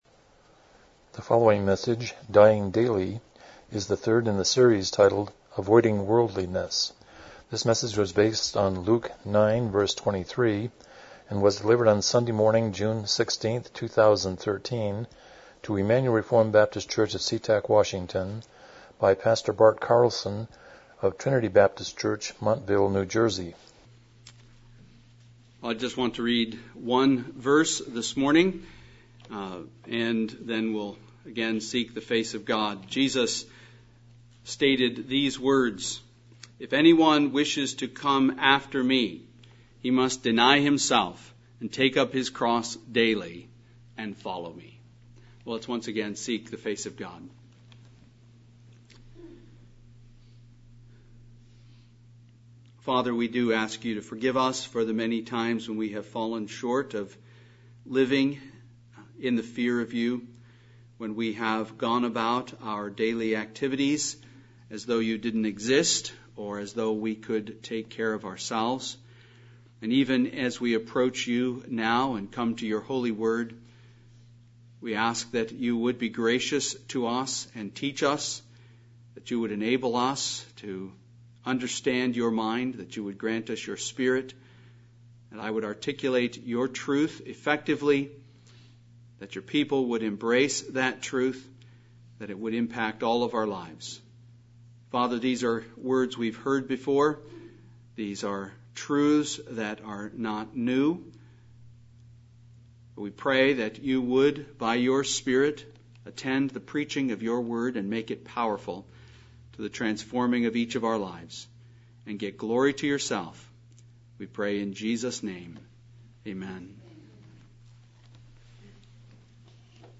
Luke 9:23 Service Type: Morning Worship « Evangelism #2 Balanced Leadership